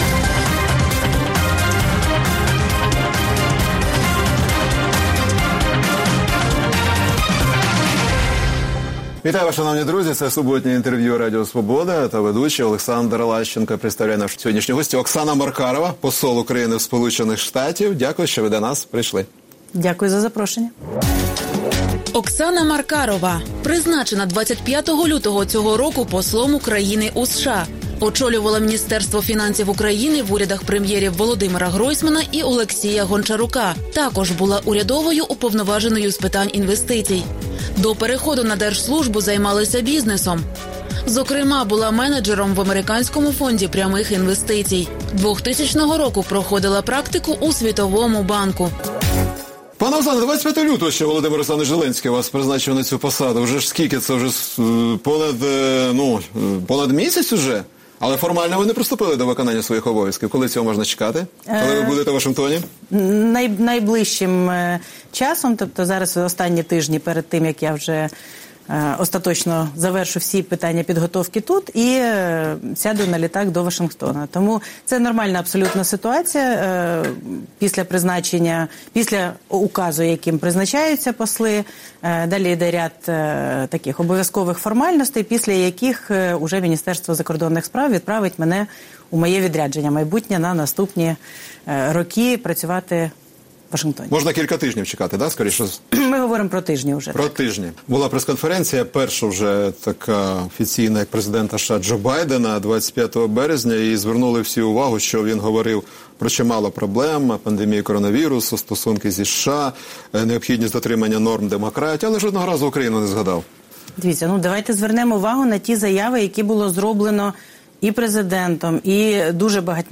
Суботнє інтерв’ю | Оксана Маркарова, посол України у США